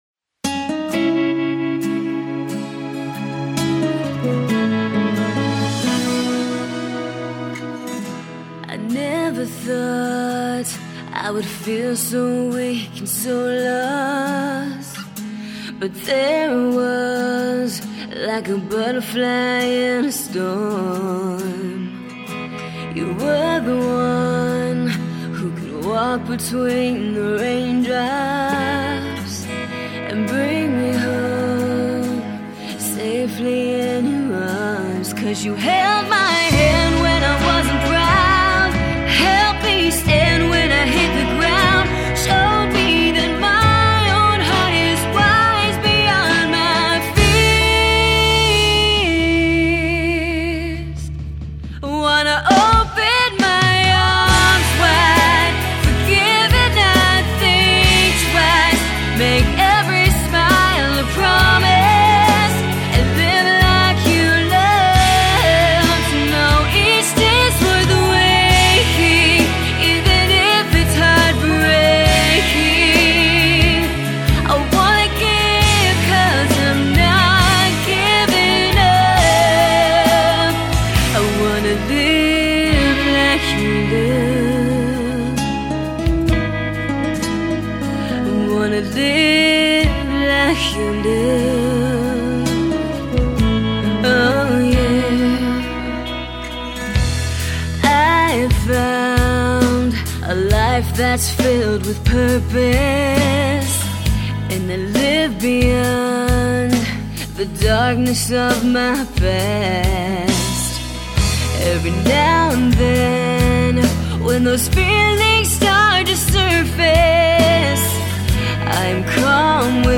(AC/pop)